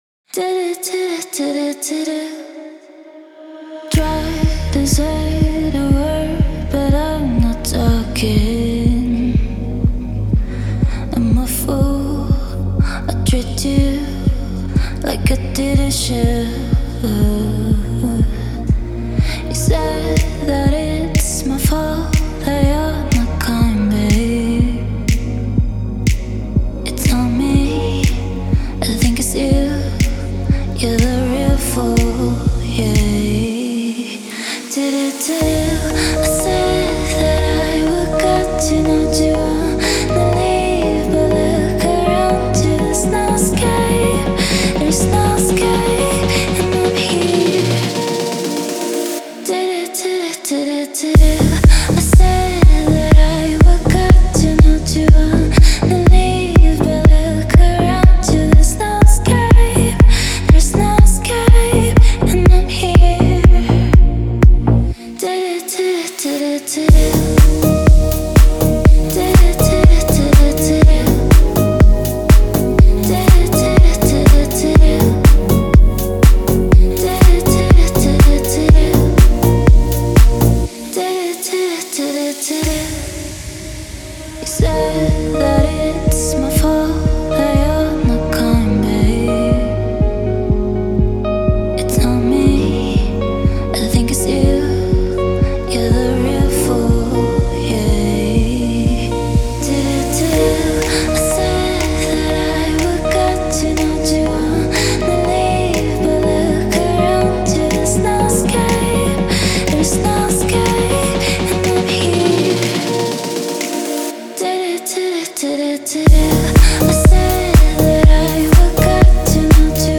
это атмосферная композиция в жанре инди-поп